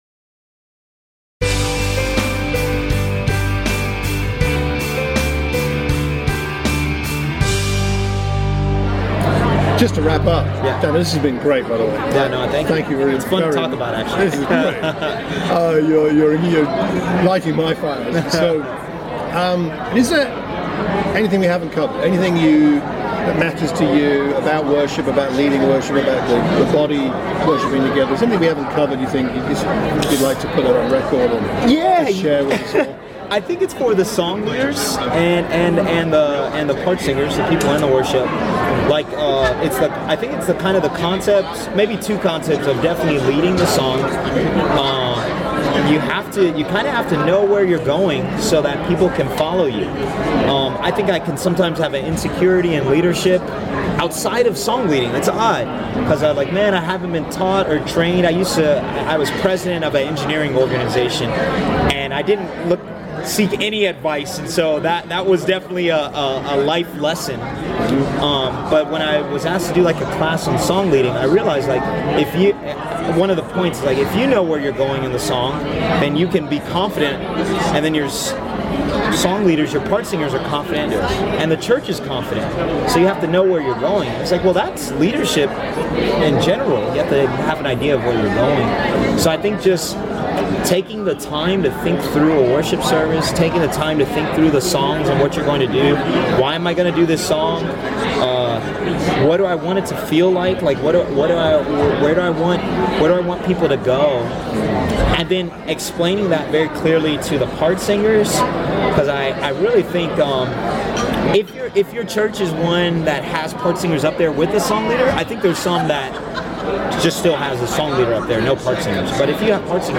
We sat and chatted about all things worship. Today's recording is bonus material from the end of our conversation.